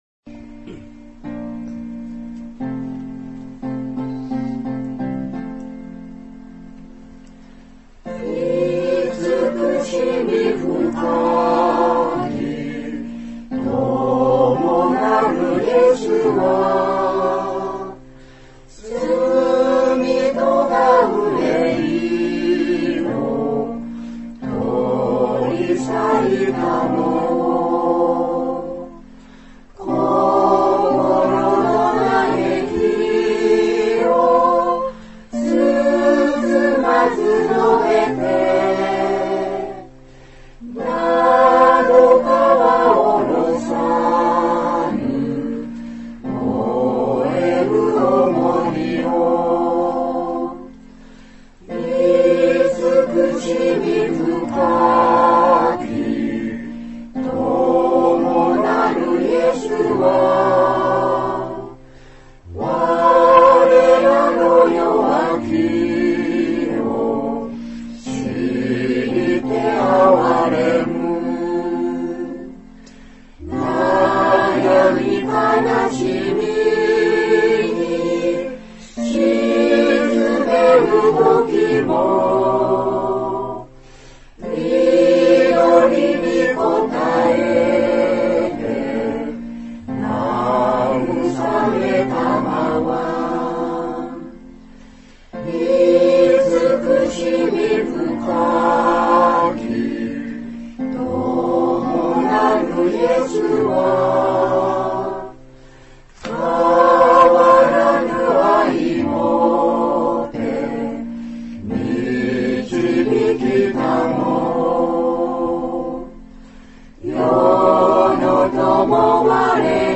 讃美歌